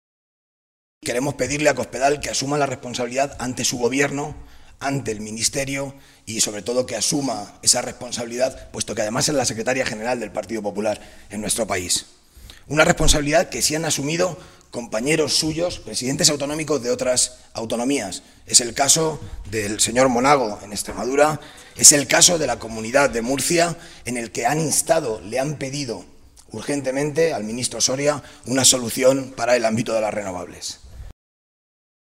Modesto Belinchón, diputado regional del PSOE de Castilla-La Mancha
Cortes de audio de la rueda de prensa